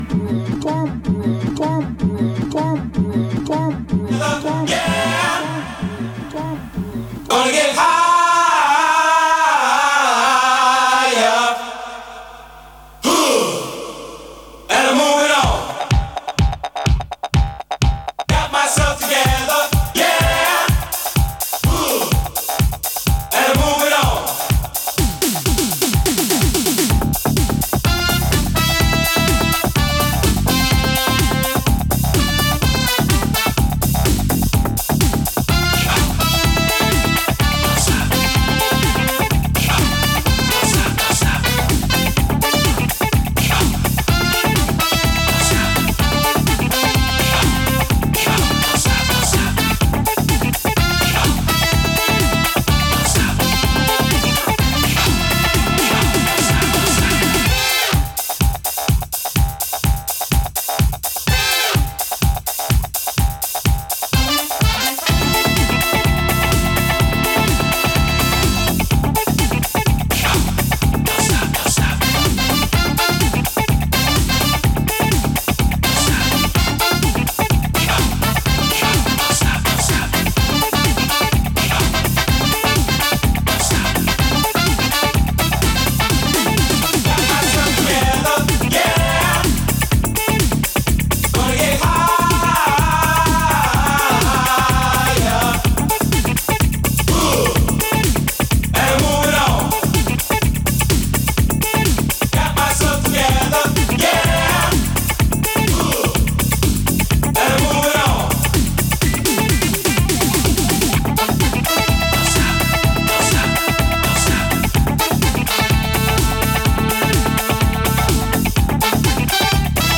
Disco/House Dub Electronic